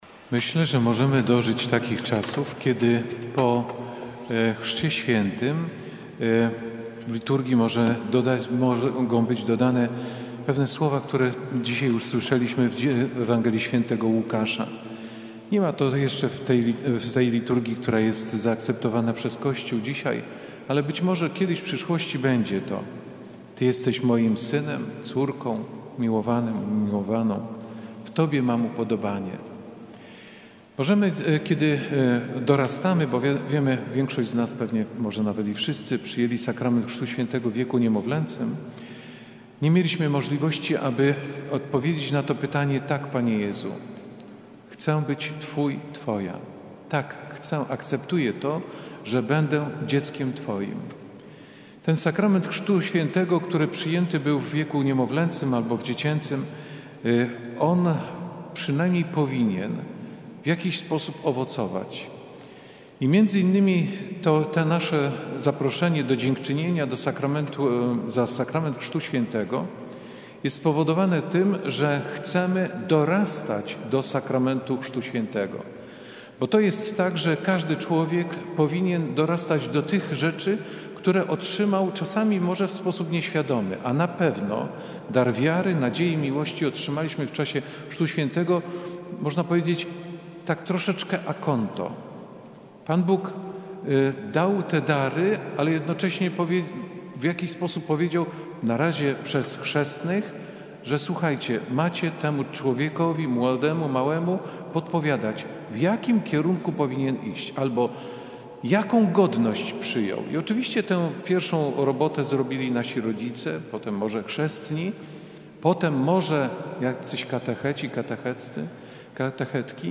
W Świątyni Opatrzności Bożej w Warszawie 8 stycznia odprawiono Mszę świętą dziękczynną za dar Chrztu Świętego.
swiatynia-homilia-na-strone.mp3